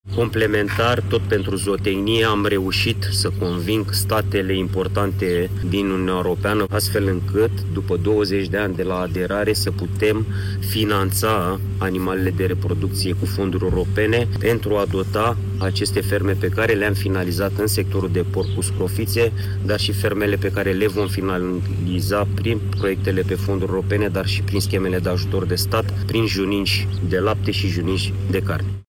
Aflat în vizită la o fermă de bovine din satul Peșteana de lângă Hațeg, ministrul Agriculturii a spus că România vrea să folosească fonduri europene pentru a crește numărul de animale și producția de carne.
Ministrul Agriculturii și Dezvoltării Rurale, Florin Barbu: „Am reușit să conving statele importante din Uniunea Europeană, astfel încât după 20 de ani de la aderare să putem finanța animalele de reproducție cu fonduri europene, pentru a dota aceste ferme”